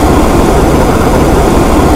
cookoff.wav